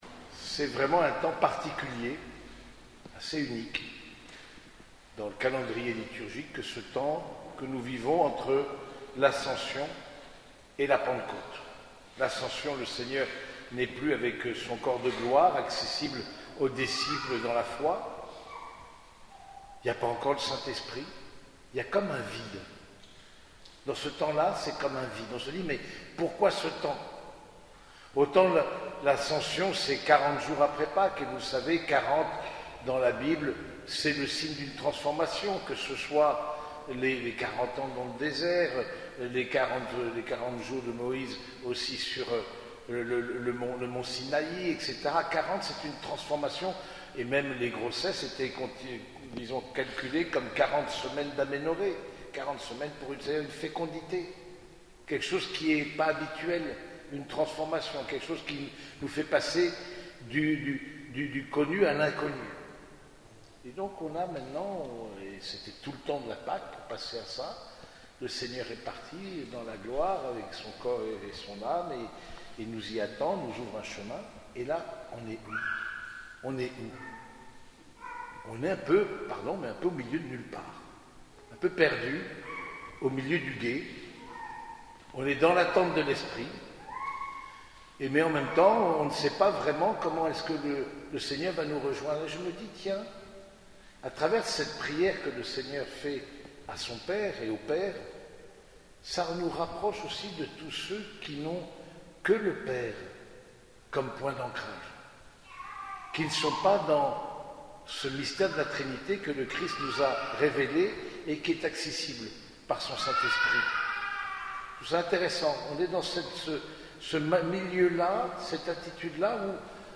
Homélie du septième dimanche de Pâques
Cette homélie a été prononcée au cours de la messe dominicale à l’église Saint-Germain de Compiègne.